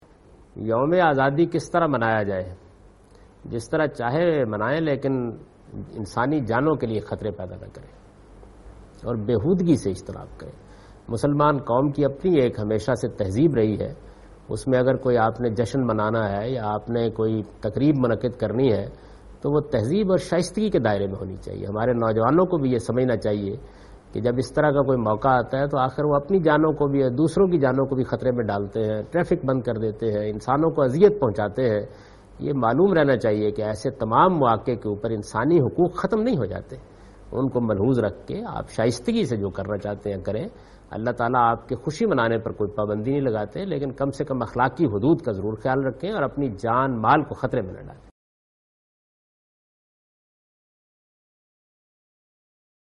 Javed Ahmad Ghamidi responds to the question 'How should independence day be celebrated'?
جاوید احمد غامدی اس سوال کہ جواب دے رہے ہیں کہ "یوم آزادی کس طرح منانا چاہئے ؟"